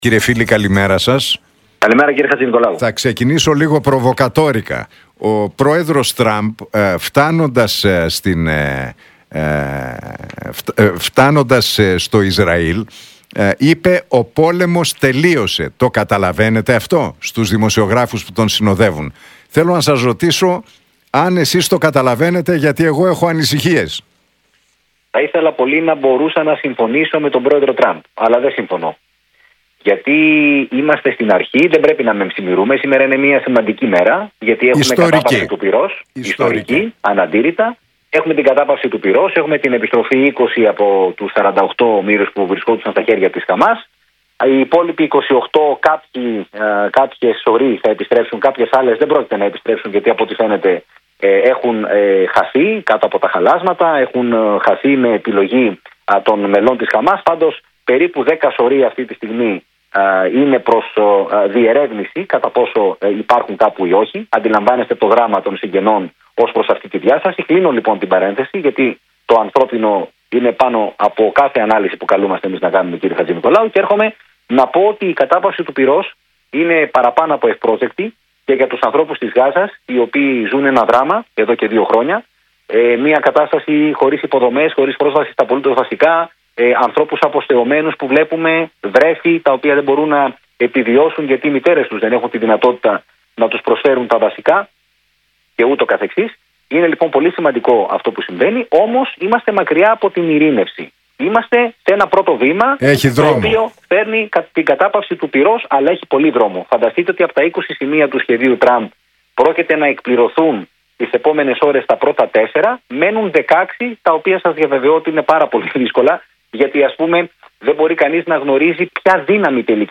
Για τις εξελίξεις στη Γάζα και τη συμφωνία Ισραήλ -Χαμάς, μίλησε στην εκπομπή του Νίκου Χατζηνικολάου στον Realfm 97,8, ο διεθνολόγος Κωνσταντίνος Φίλης.